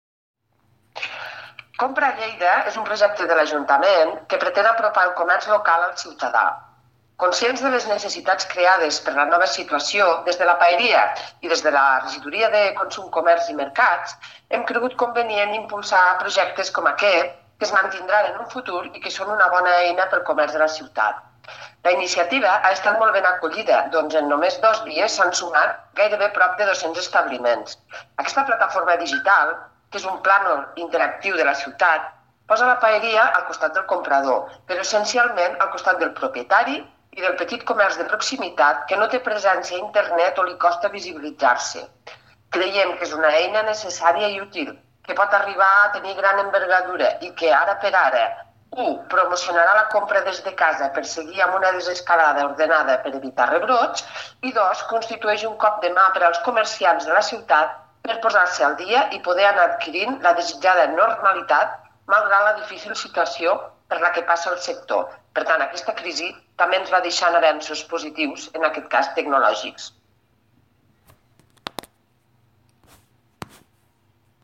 tall-de-veu-de-la-regidora-de-comerc-mercats-i-consum-marta-gispert-sobre-la-nova-plataforma-digital-compra-a-lleida